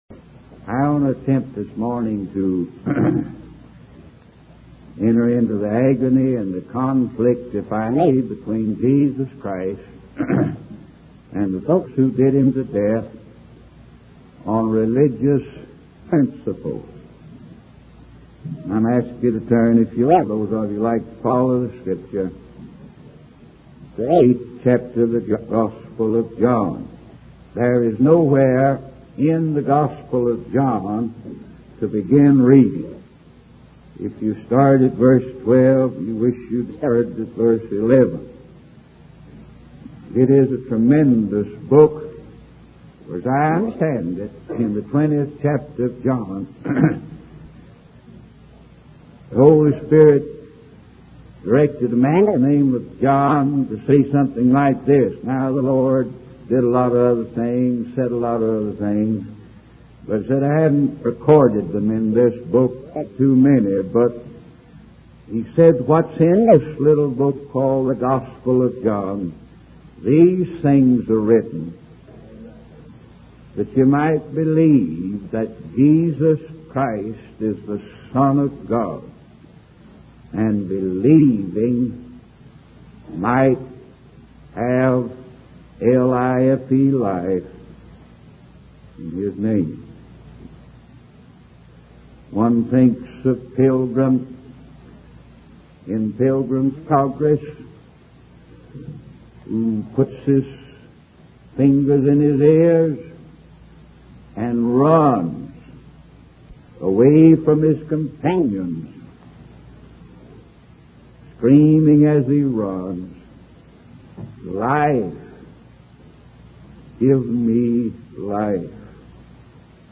In this sermon, the preacher emphasizes the importance of believing in Jesus Christ as the only way of escape from sin and eternal damnation. He highlights the fact that Jesus' sacrifice on the cross was necessary for salvation and that no one can save themselves. The preacher urges the congregation to make a sincere effort to come to a true belief in Jesus and to seek Him with all their hearts.